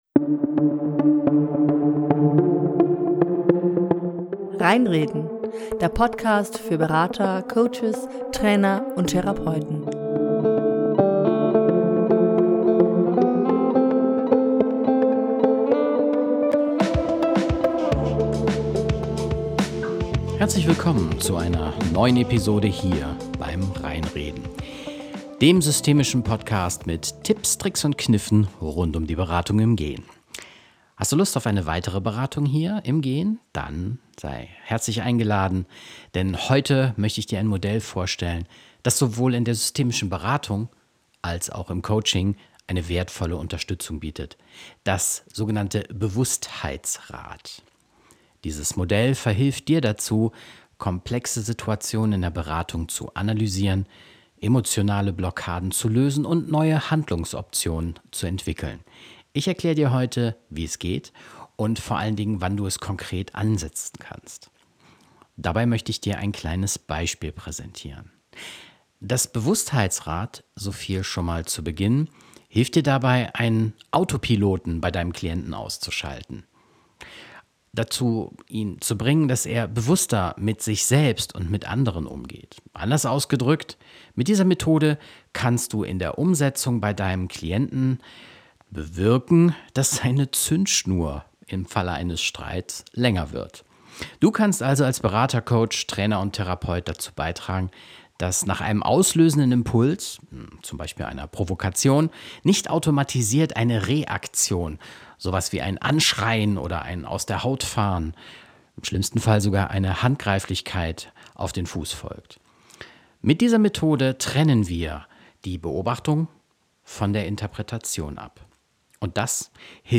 Während des Spaziergangs führte ich Dich durch die fünf Elemente des Bewusstheitsrads (Wahrnehmen, Denken, Fühlen, Wünschen, Handeln) und lade dich ein, diese auf eine aktuelle Konfliktsituation anzuwenden.